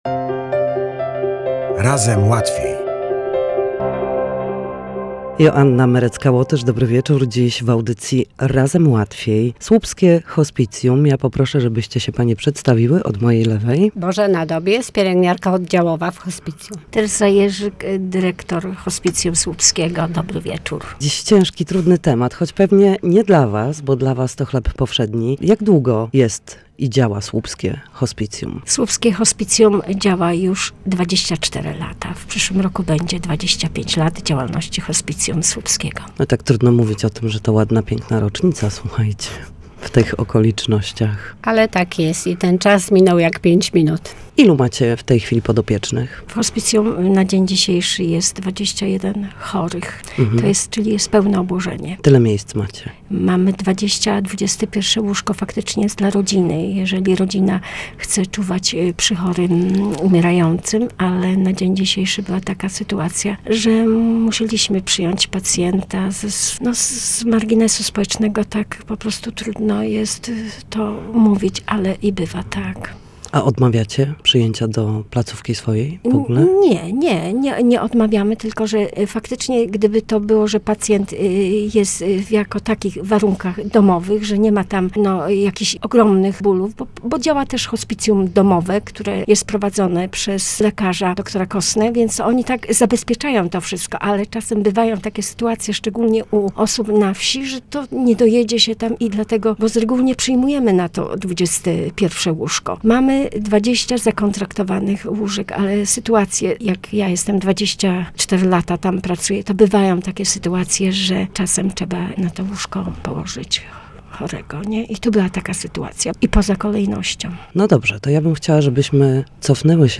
W audycji „Razem Łatwiej” dwie cudowne kobiety